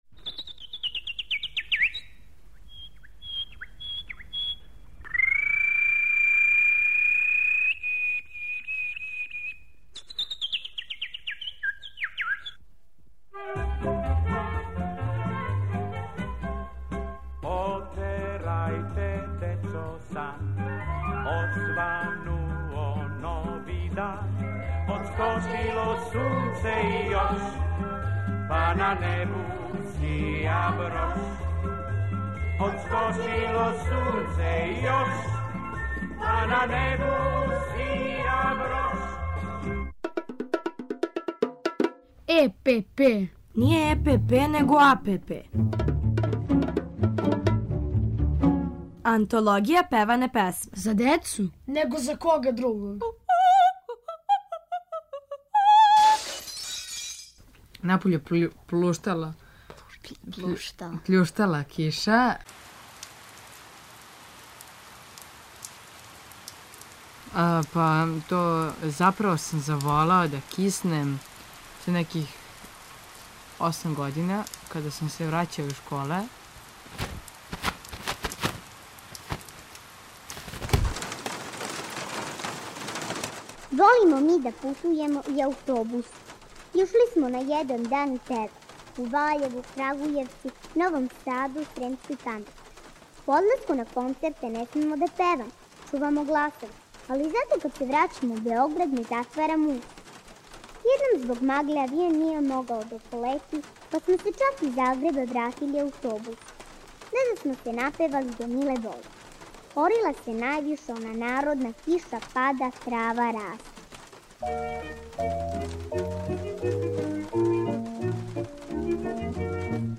У серијалу Антологија певане песме, певамо и причамо о киши, уз Колибри и Дечију драмску групу Радио Београда.